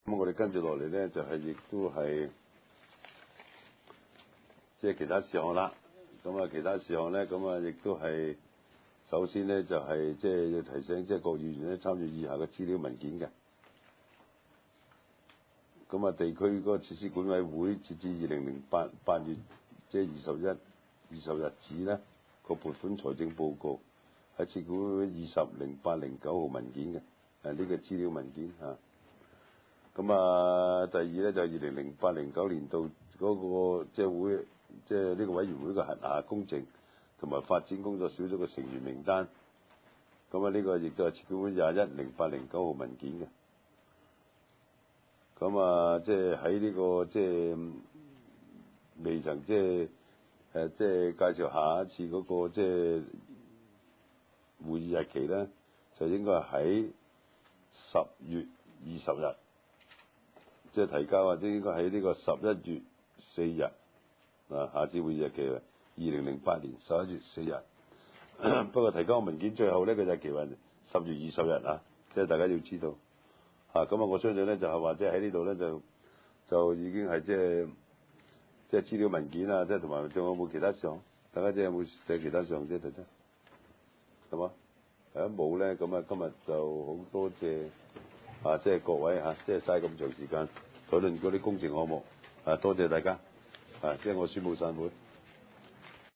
地區設施管理委員會第五次會議
荃灣民政事務處會議廳